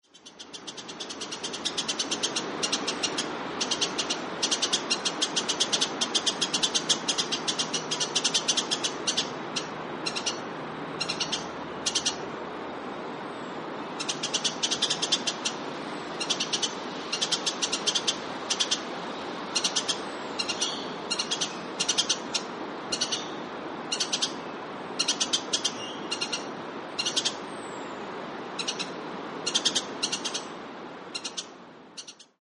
New Holland Honeyeater - Phylidonyris novaehollandiae
Voice: loud staccato chip, intermittent 'chez', chattering alarm call.
New_Holland_Hon_chatter.mp3